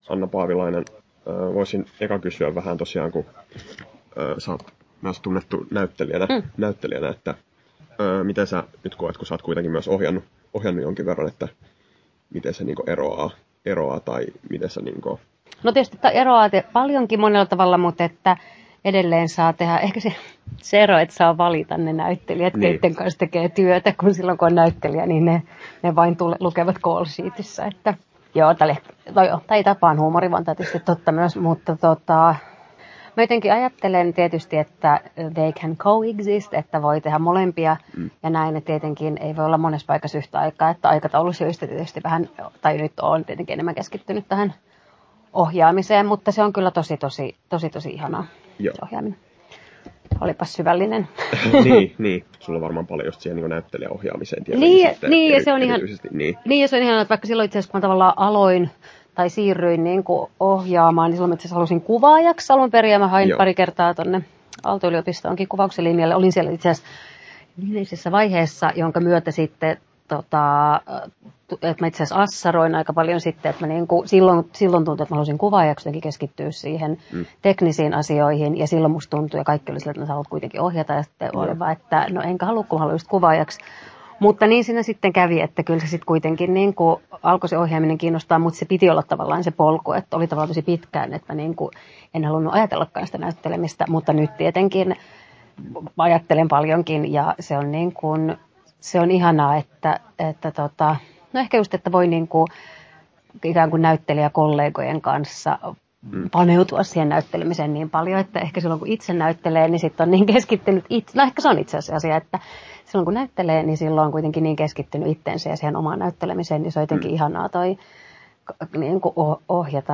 17'52" Tallennettu: 6.10.2022, Turku Toimittaja